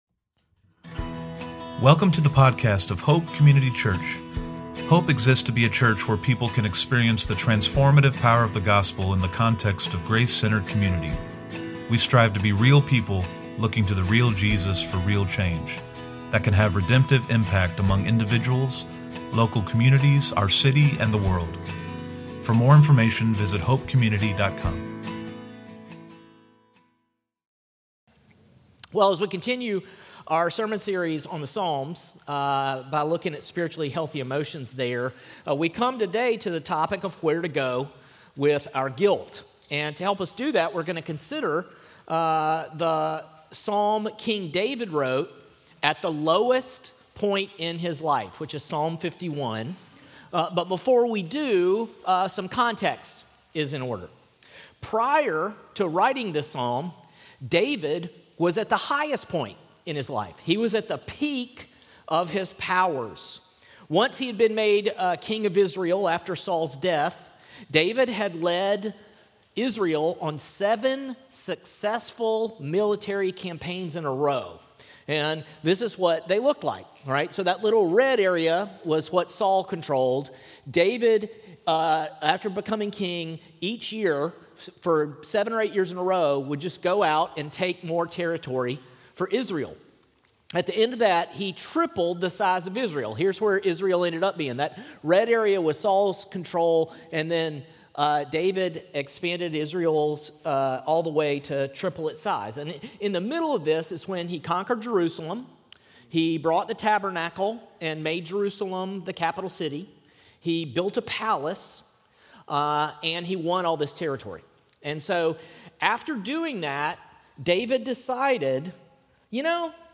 OP-Sermon-11.2.25.mp3